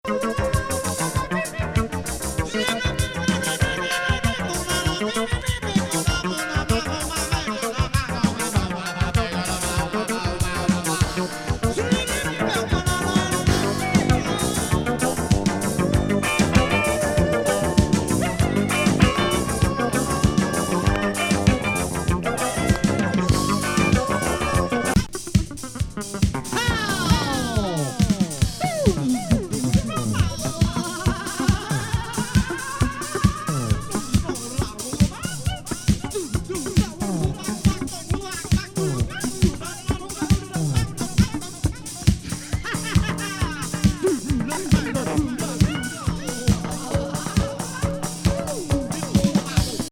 ポーランドのアヴァン・ジャズ・ロック・グループ82年作。